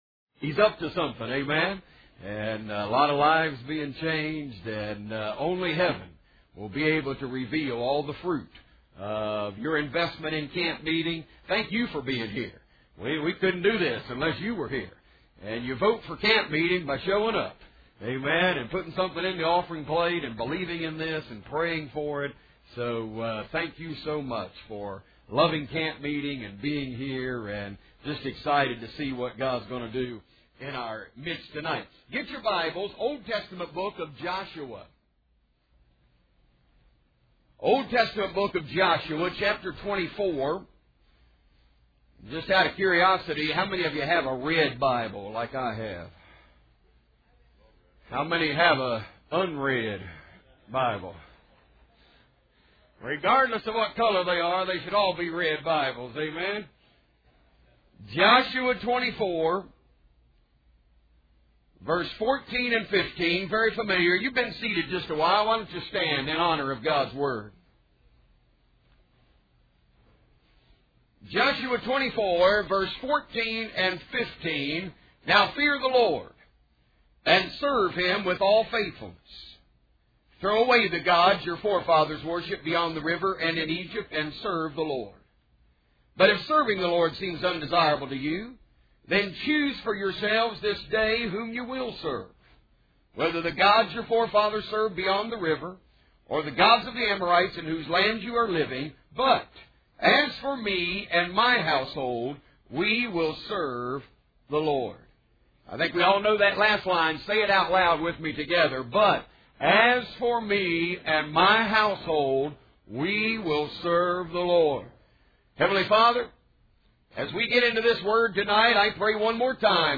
Sermons from the 2013 Taylor County Campmeeting - Taylor County Campmeeting, Butler, GA